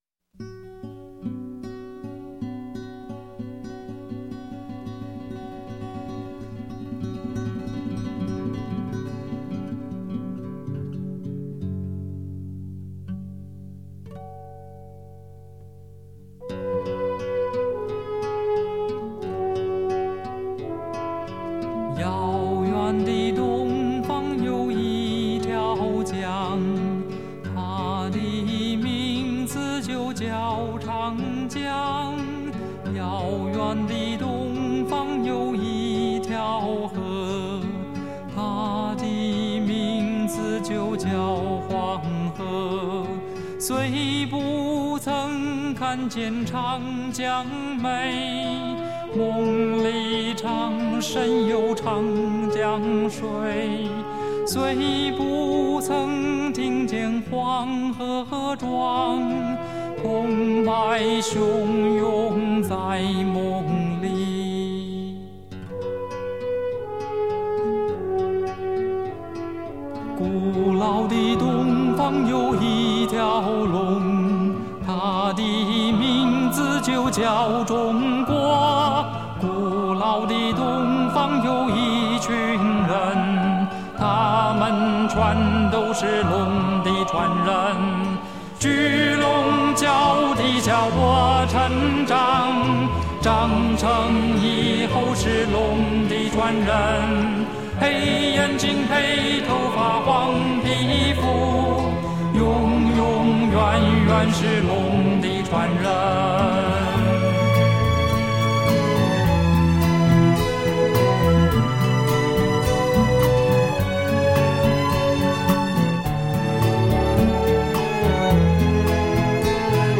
他的声音清亮昂扬，形象端正，是彼时“现代中国青年”的最佳代言人，而其所灌唱歌曲，多与此一形象吻合。